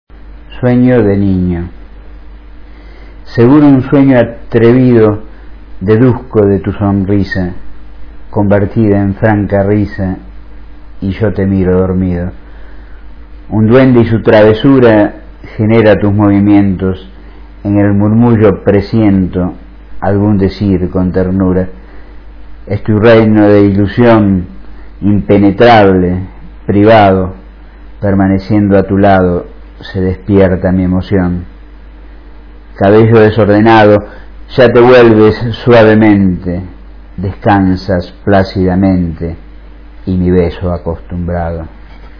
Recitado por el autor (0:42", 165 KB)